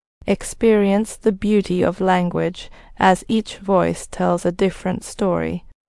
en-female-2.mp3